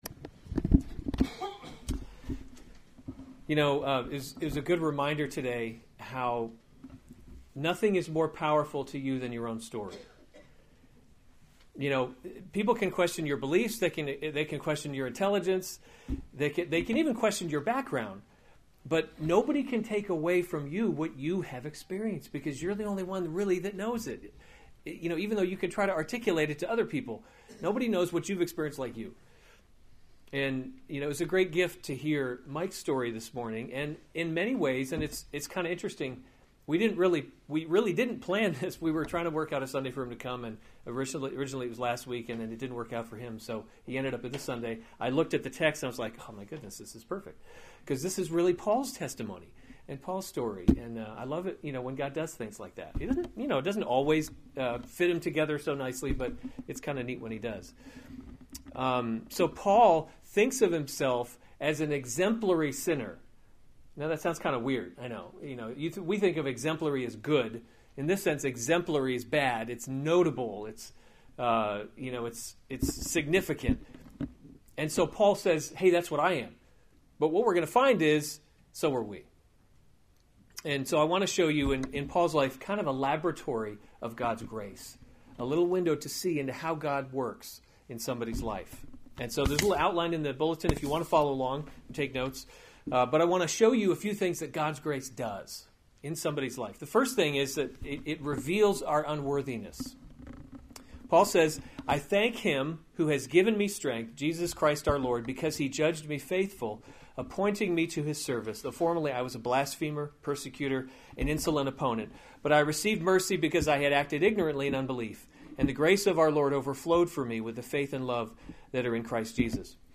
February 18, 2017 1 Timothy – Leading by Example series Weekly Sunday Service Save/Download this sermon 1 Timothy 1:12-17 Other sermons from 1 Timothy Christ Jesus Came to Save Sinners […]